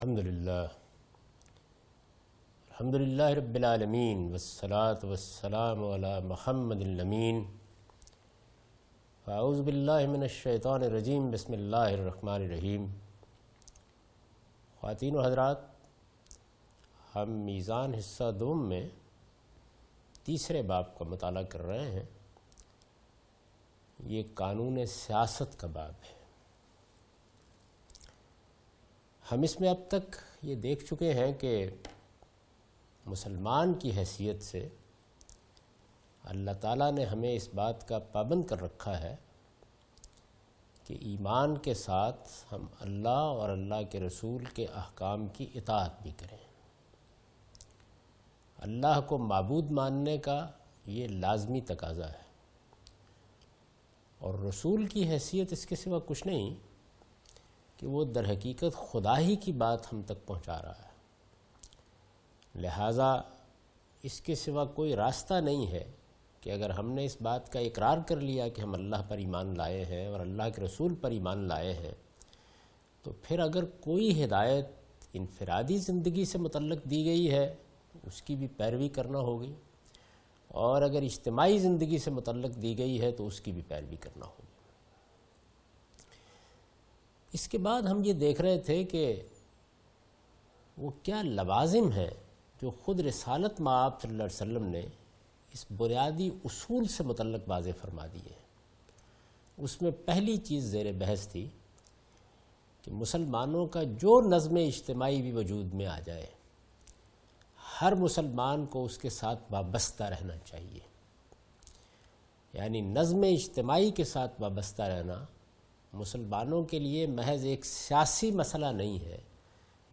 A comprehensive course on Islam, wherein Javed Ahmad Ghamidi teaches his book ‘Meezan’.
In this lecture he teaches the topic 'The Political Shari'ah' from 2nd part of his book. It is discussed in this lecture that when we are allowed to rebel against our own 'Islamic government'.